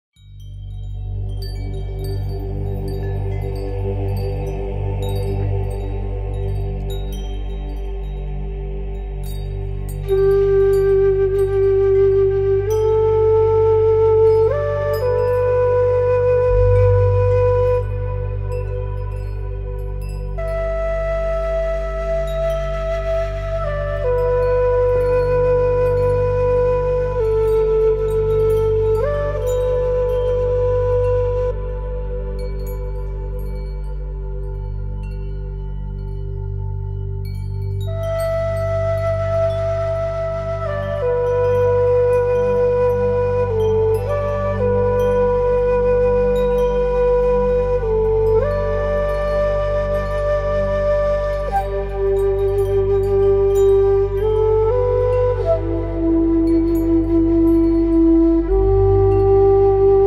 463HZ Binaural sound increase sound effects free download